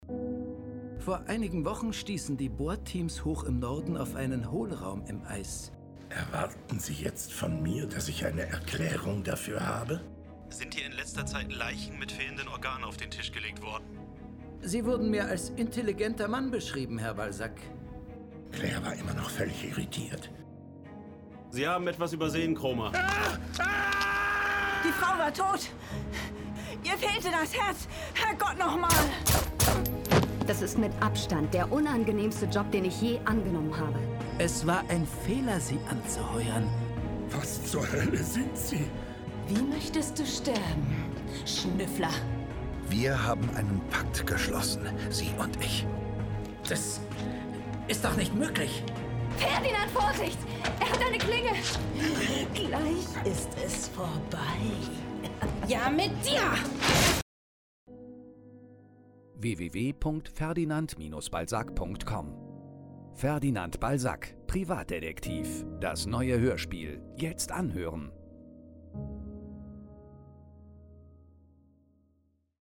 Insgesamt hat sich etwas an der Soundkulisse getan, auch wenn es nach meinem Empfinden immer noch mehr sein dürfte. Auch Musik ist „sparsam“ eingesetzt, dafür doch stimmungsvoll.
Die Sprecher sind in Ihrer Qualität gleich geblieben.
Aber auch das restliche Ensemble schlägt sich sehr gut.